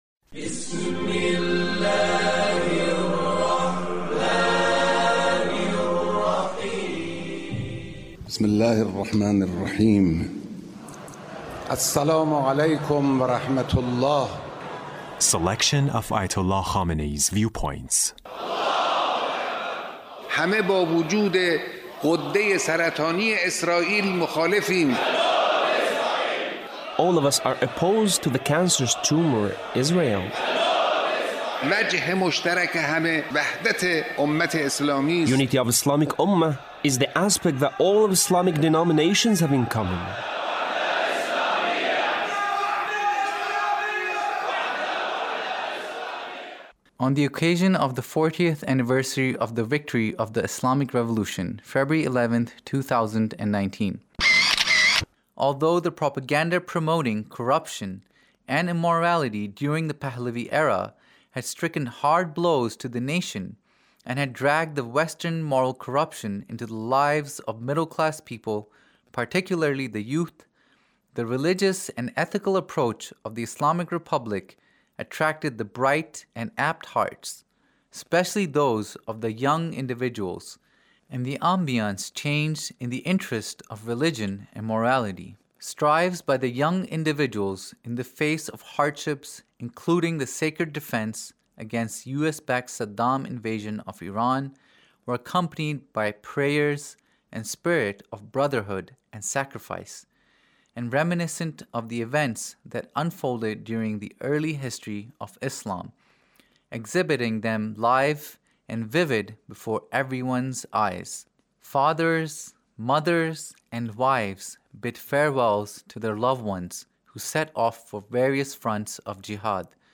Leader's Speech (1881)